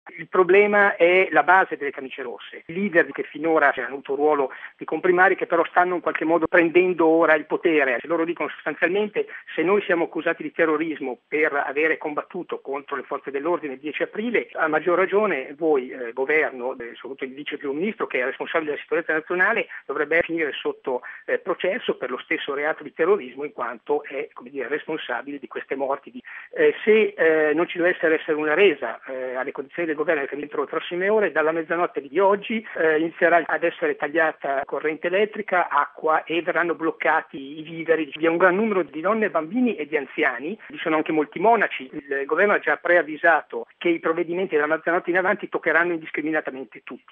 raggiunto telefonicamente nella capitale thailandese